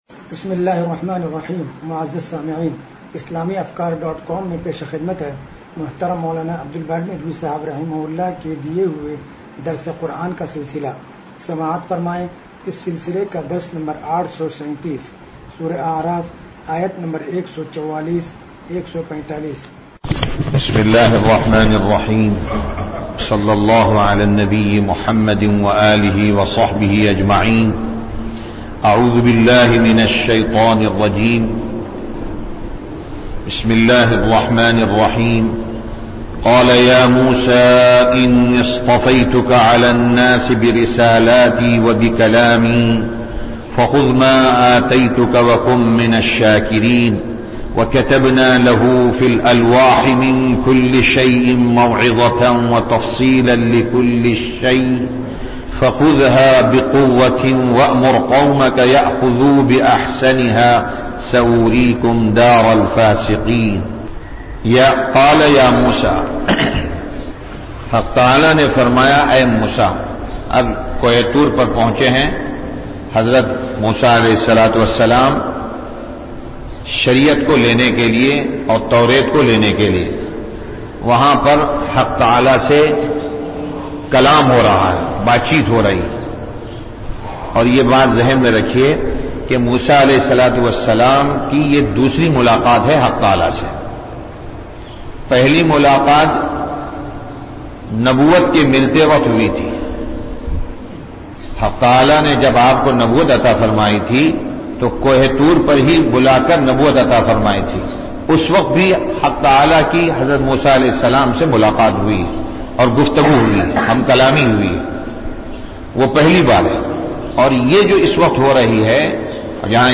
درس قرآن نمبر 0837